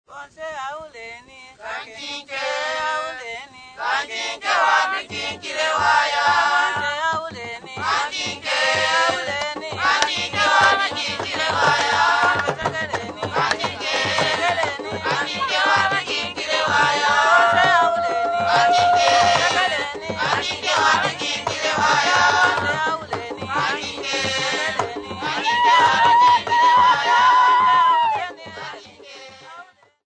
Men and women
Folk music--Africa
Field recordings
A dance song for the hunting dance Mabila accompanied by drums.